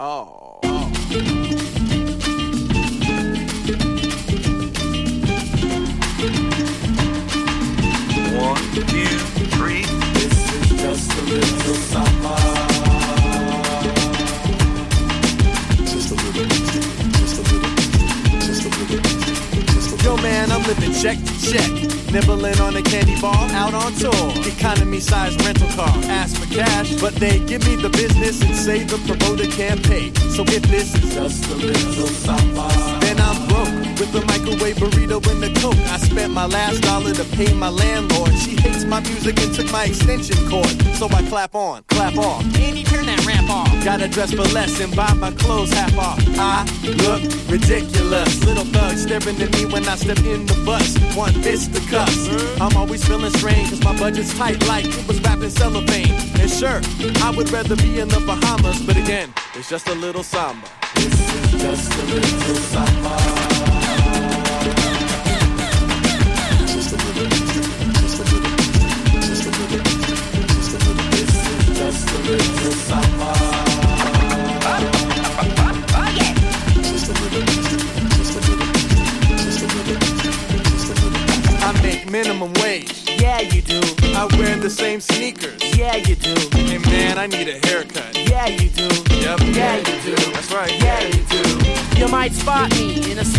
最強サンバ・ヒップホップをリミックス！